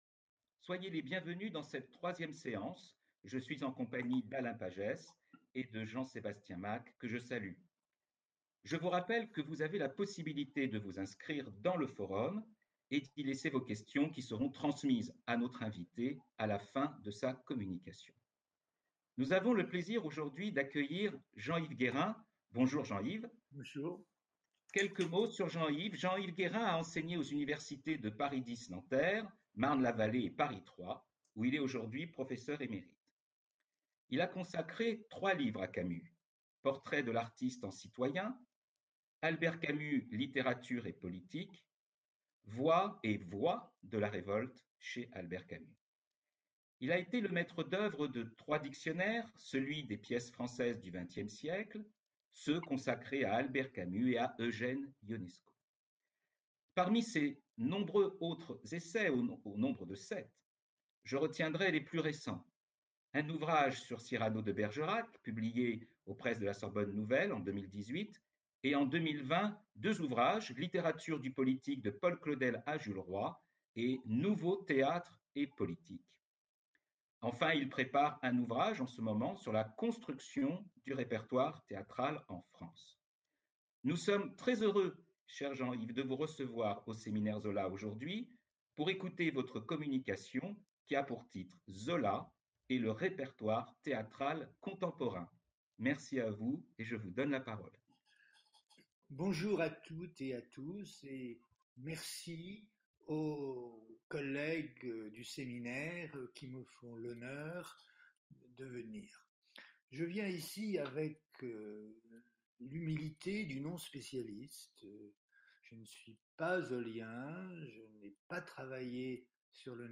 Les articles du Bien public et du Voltaire réunis dans Nos Auteurs dramatiques feront l’objet de cette conférence. Il y sera question des classiques, de Victor Hugo, de l’école du bon sens, de Labiche et d’auteurs aujourd’hui oubliés. Zola y sera confronté à d’autres critiques de son époque, des écrivains et des journalistes.